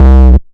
Fat Double Short (JW2).wav